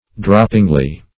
droppingly - definition of droppingly - synonyms, pronunciation, spelling from Free Dictionary Search Result for " droppingly" : The Collaborative International Dictionary of English v.0.48: Droppingly \Drop"ping*ly\, adv.